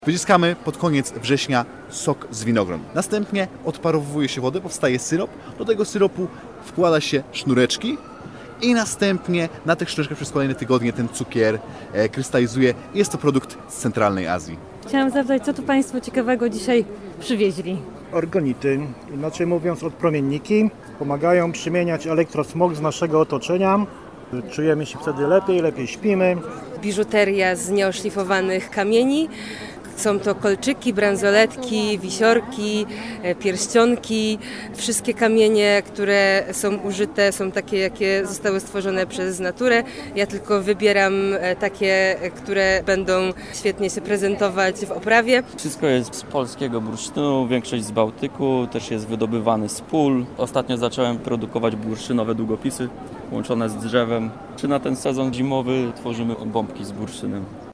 Ja tylko wybieram te, które będą się świetnie prezentować w oprawie – mówi jedna ze sprzedających na Giełdzie Minerałów, Biżuterii i Skamieniałości w łódzkiej Zatoce Sportu.
Ostatnio zacząłem produkować bursztynowe długopisy oraz bombki z bursztynu na sezon zimowy – dodaje kolejny sprzedawca.